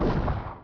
Perc [Thunder](1).wav